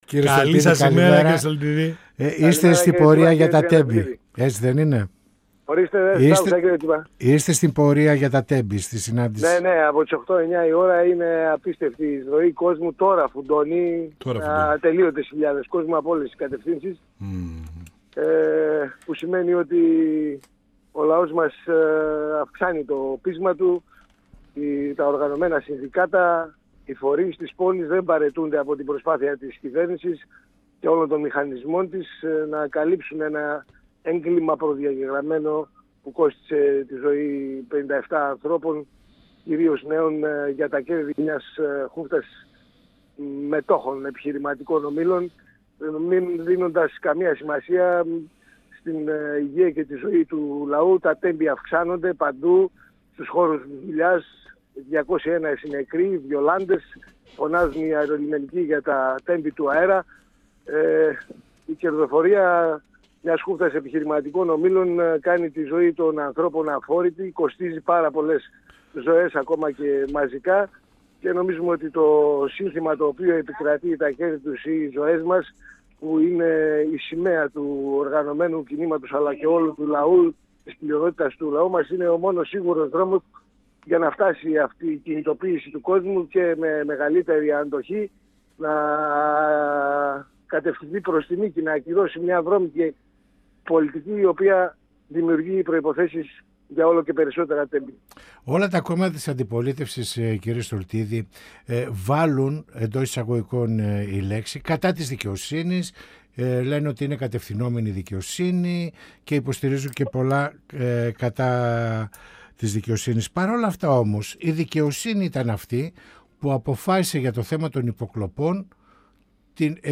Ο Βουλευτής Β΄ Θεσσαλονίκης του ΚΚΕ Λεωνίδας Στολτίδης στον 102FM της ΕΡΤ3 | «Πανόραμα Επικαιρότητας» | 28.02.2026